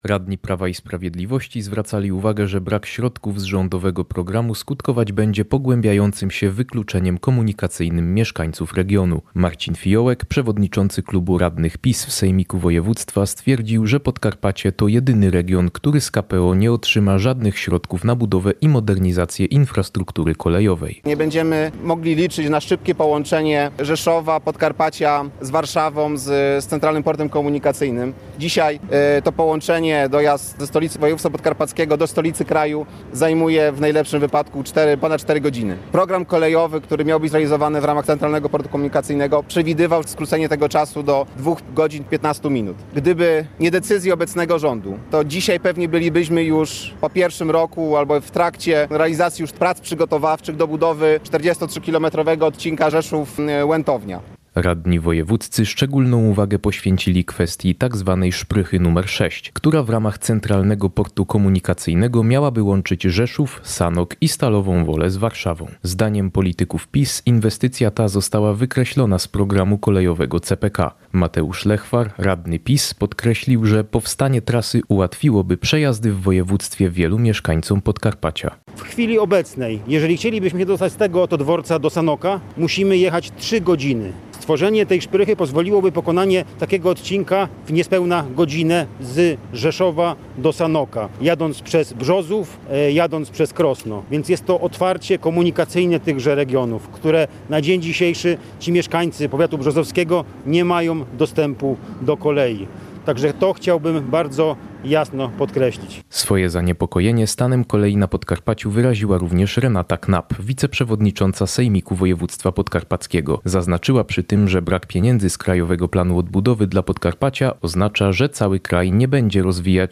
Podczas konferencji prasowej w Rzeszowie Marcin Fijołek, Renata Knap i Mateusz Lechwar skrytykowali usunięcie tzw. szprychy nr 6 z programu kolejowego Centralnego Portu Komunikacyjnego.